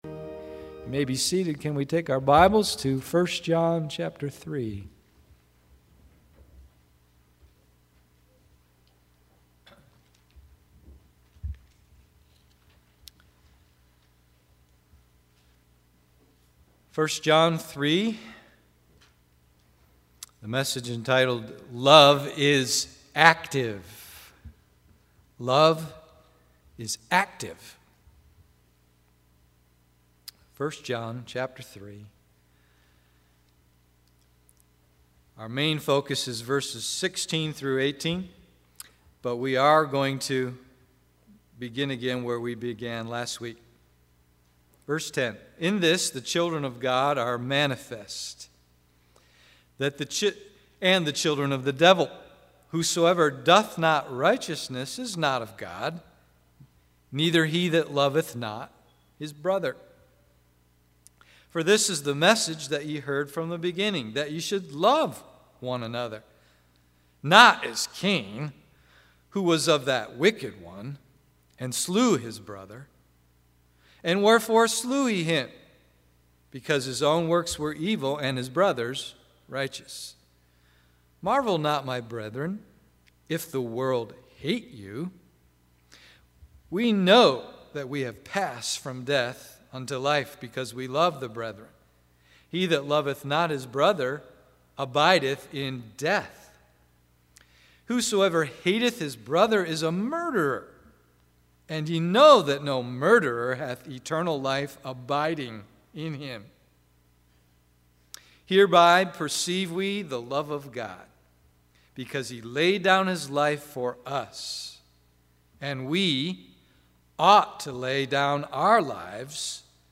Love is Active AM Service